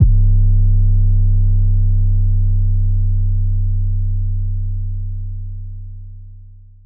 MEET MR. NICEGUY 808.wav